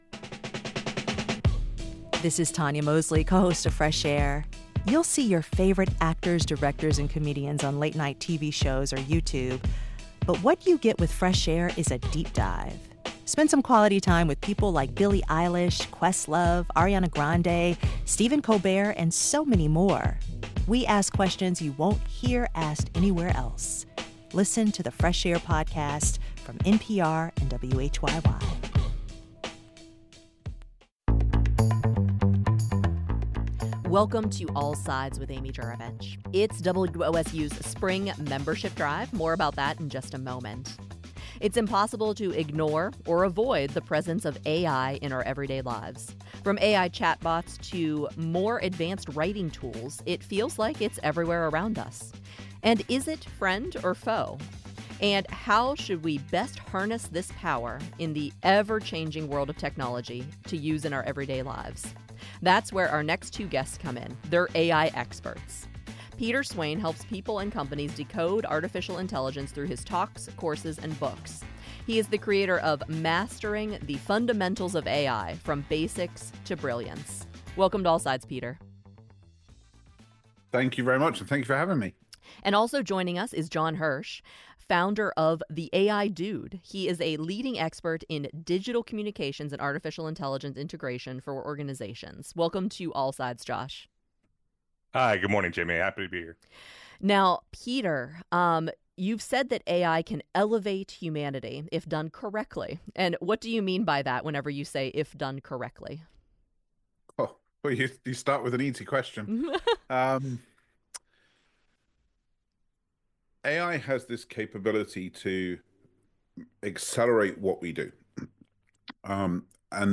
Untangled: Why is the healthcare system so complicated?. Weekly reporter roundtable.